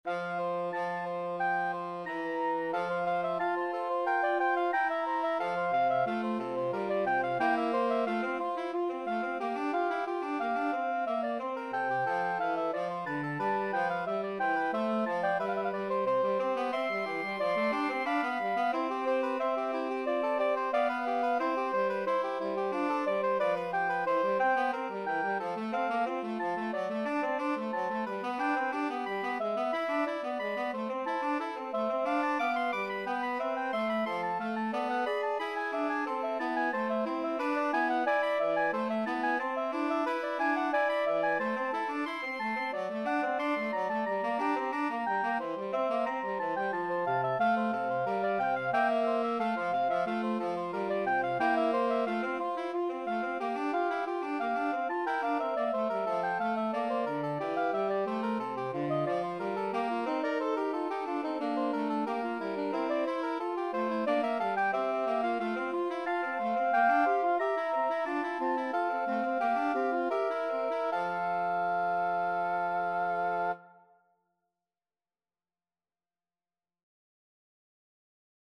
Soprano Saxophone 1Soprano Saxophone 2Tenor Saxophone
4/4 (View more 4/4 Music)
F major (Sounding Pitch) (View more F major Music for Woodwind Trio )
Woodwind Trio  (View more Advanced Woodwind Trio Music)
Classical (View more Classical Woodwind Trio Music)